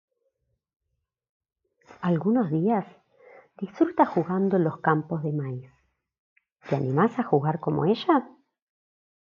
AUDIOCUENTO BASADO EN LA CANCION DE JUDITH AKOSCHKY